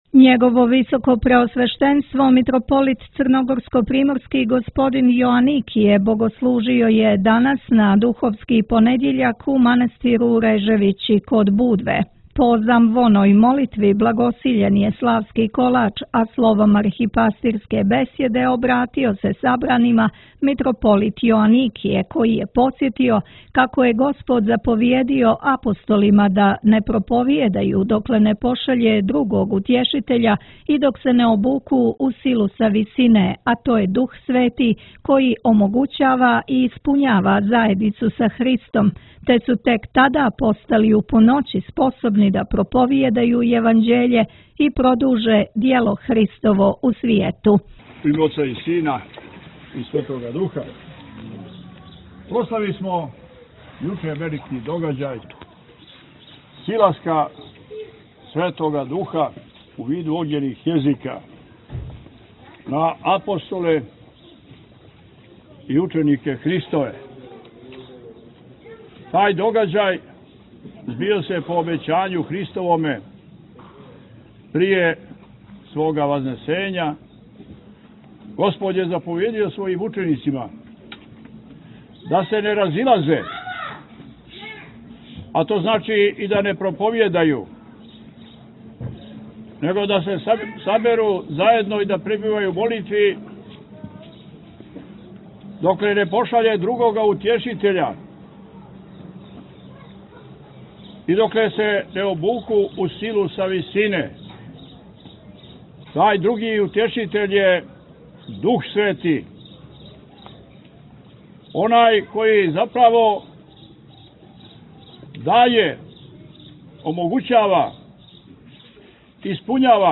Njegovo visokopreosveštenstvo Mitropolit crnogorsko-primorski Joanikije, bogoslužio je na Duhovski ponedeljak u manastiru Reževići.